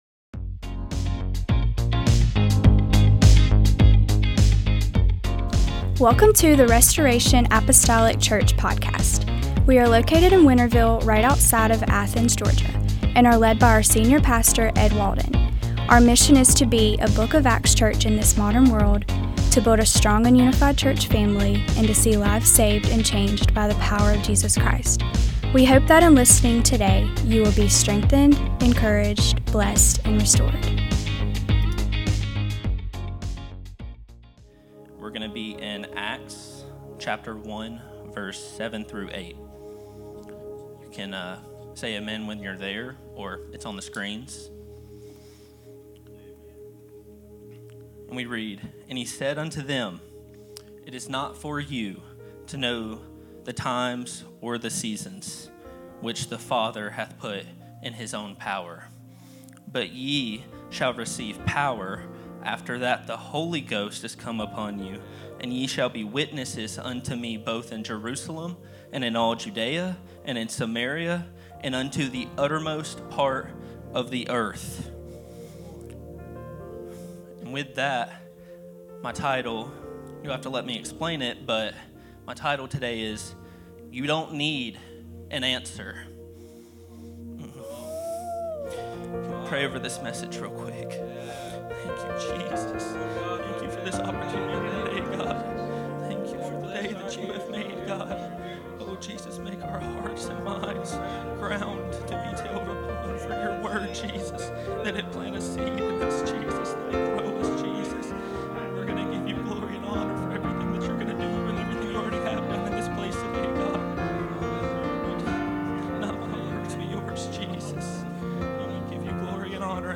Sunday Service